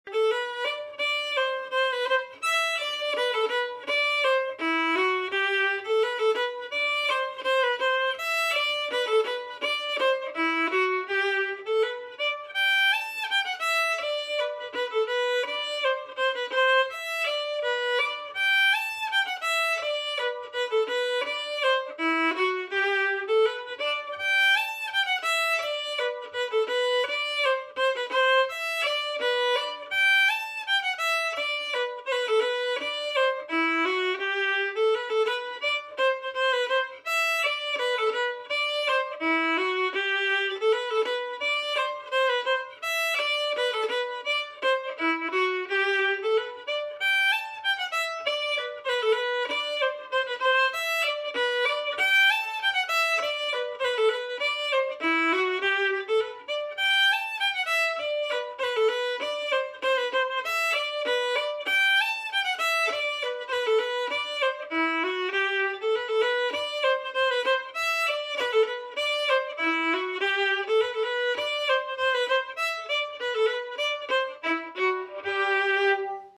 Key: G
Form: Reel
Played slowly, then up to tempo
Region: Québec